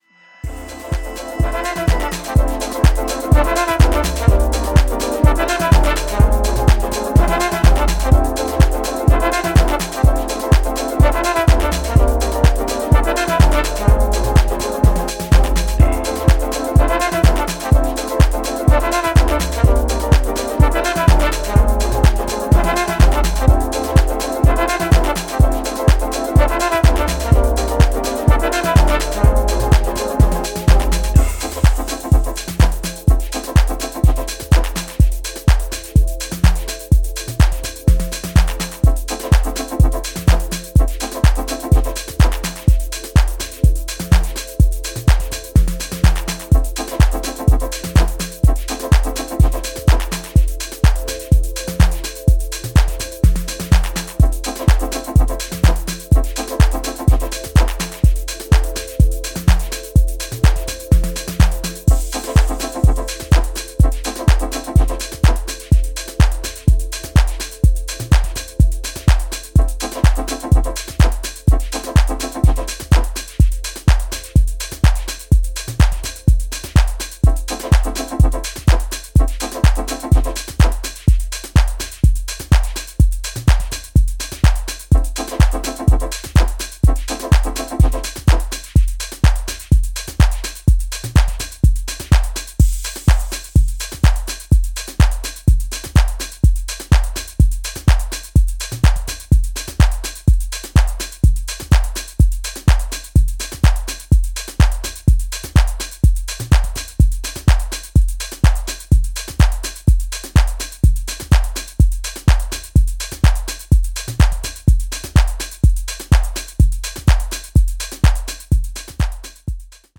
> HOUSE・TECHNO
ジャンル(スタイル) DEEP HOUSE / DETROIT / HOUSE / TECHNO